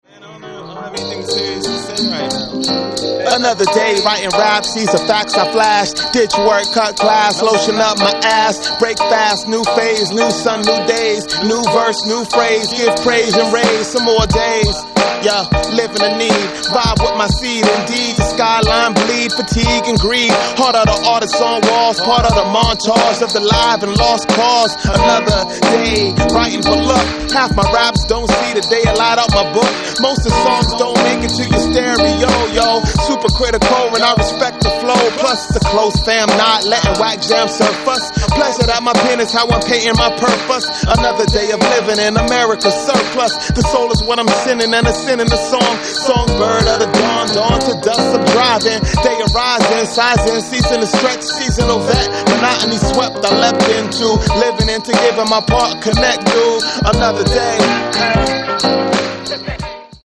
The indie Hip-Hop legacy continues to grow.